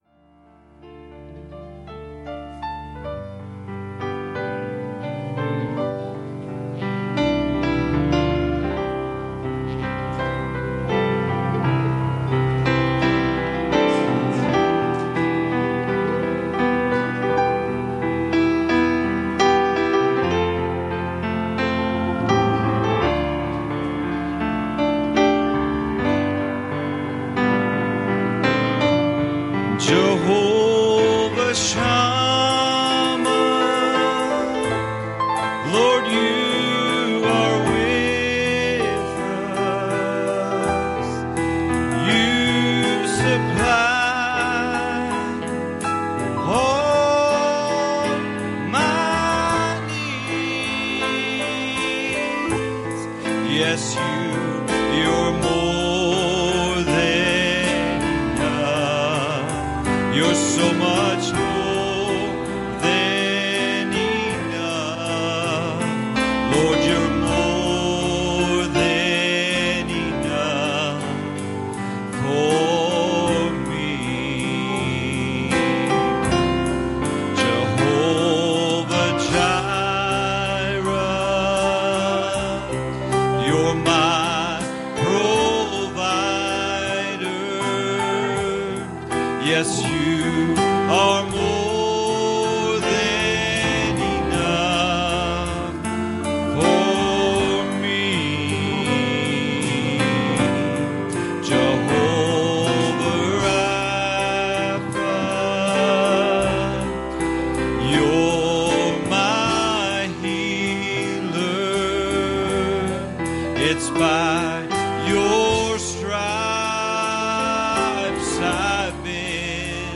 1 Kings 19:5 Service Type: Wednesday Evening "We need a juniper tree.